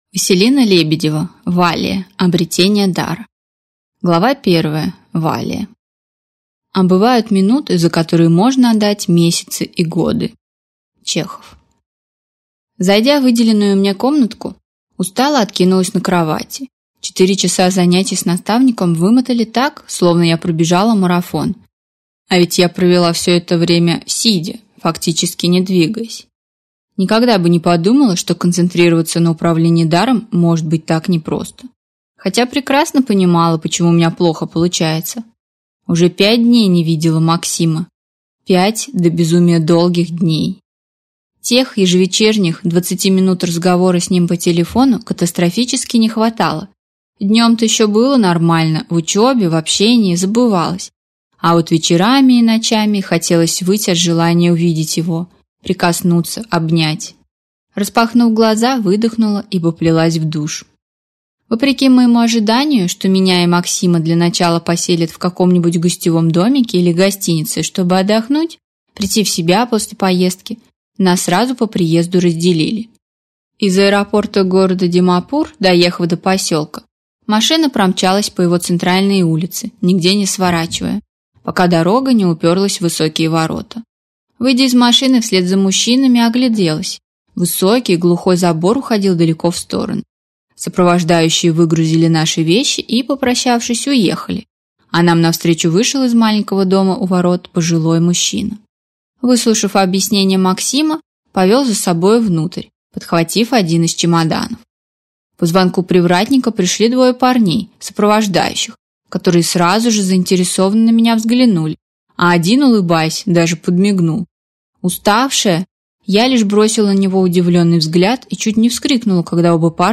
Аудиокнига Валлия. Обретение дара | Библиотека аудиокниг